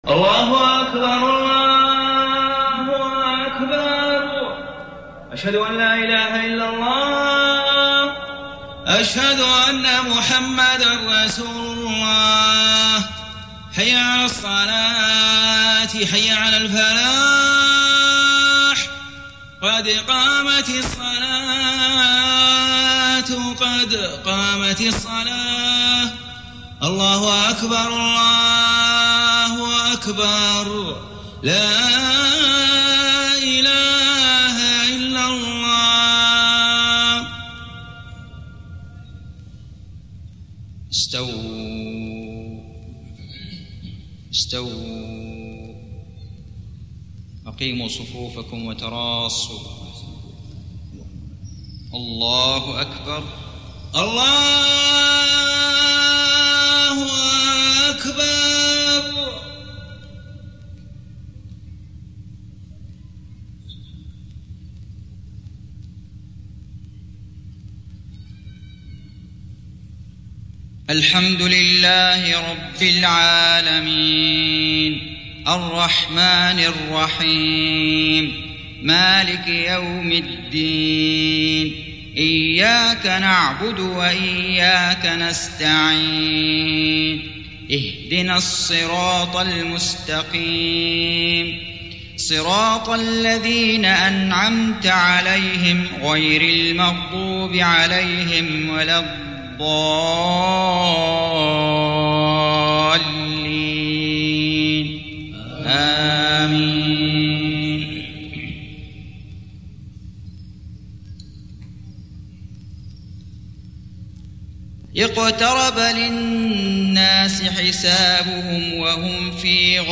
صلاة العشاء 24 محرم 1430هـ فواتح سورة الأنبياء 1-18 > 1430 🕋 > الفروض - تلاوات الحرمين